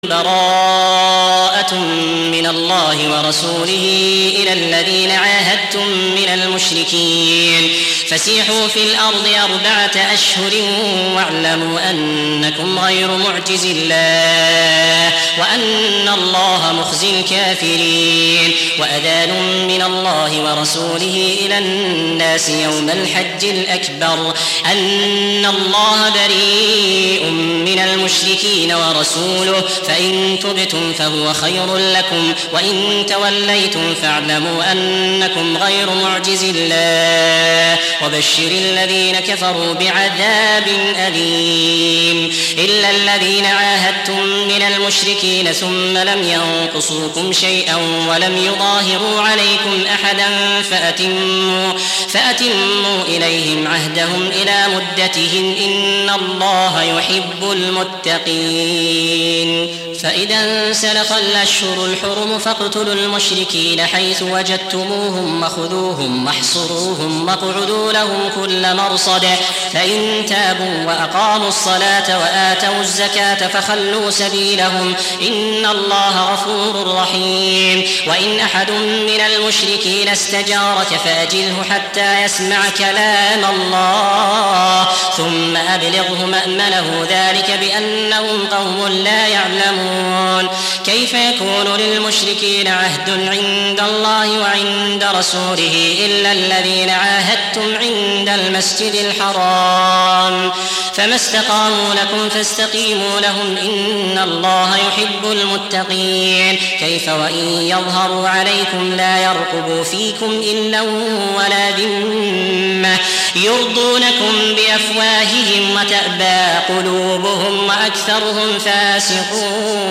Surah Sequence تتابع السورة Download Surah حمّل السورة Reciting Murattalah Audio for 9. Surah At-Taubah سورة التوبة N.B *Surah Excludes Al-Basmalah Reciters Sequents تتابع التلاوات Reciters Repeats تكرار التلاوات